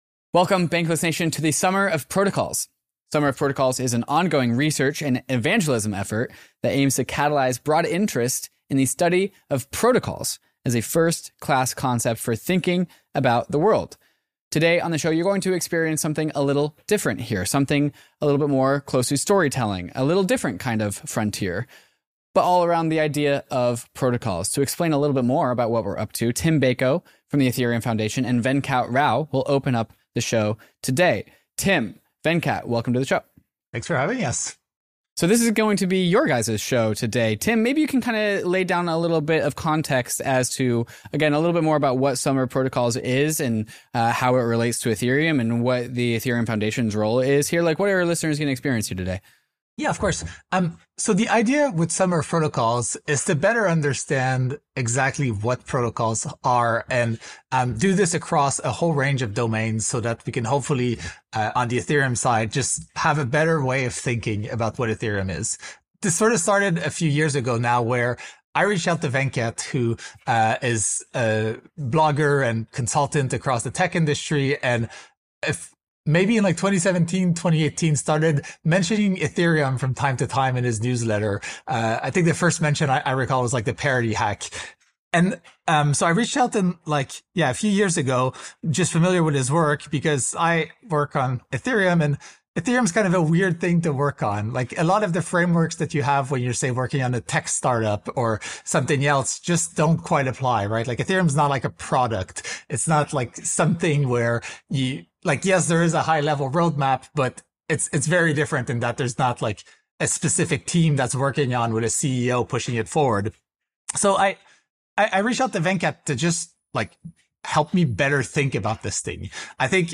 Additionally, this episode contains three short interviews with three of the 35 total Summer of Protocols researchers.